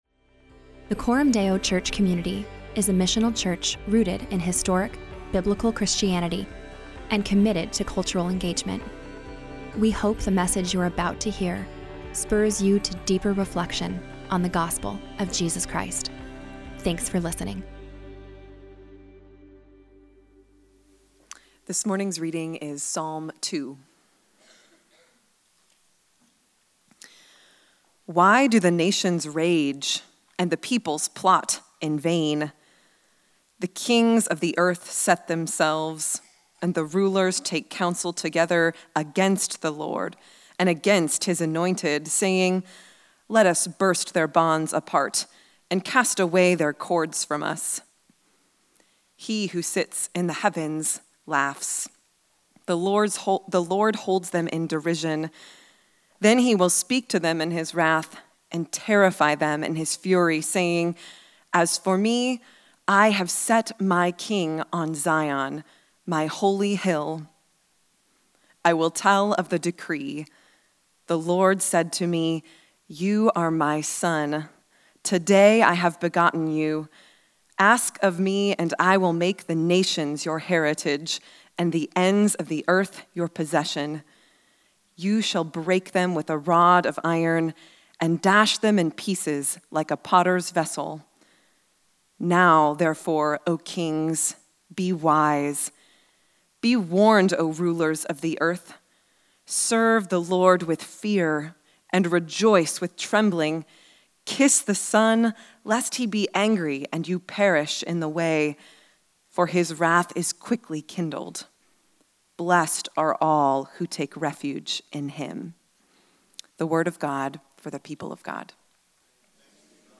The nations will rage, but God’s people can REST. In this sermon, we explore how the rule and reign of Jesus gives us peace as nations and empires rise and fall.